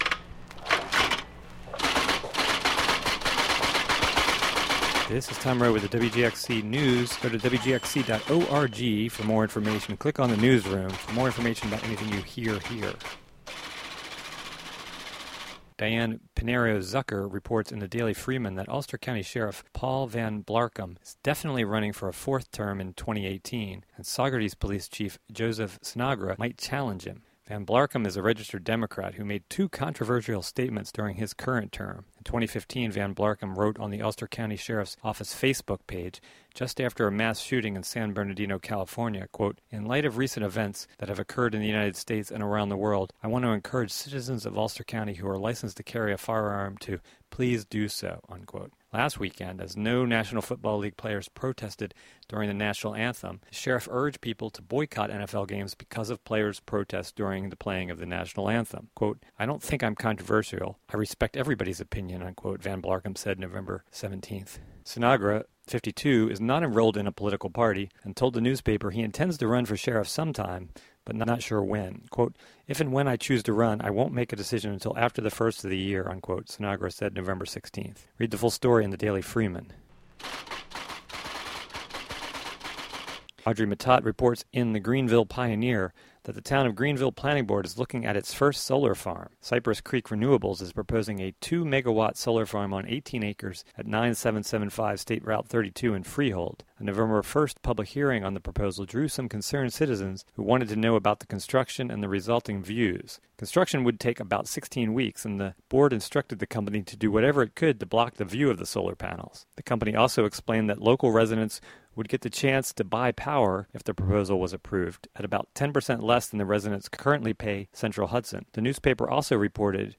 WGXC daily headlines for Nov. 17, 2017.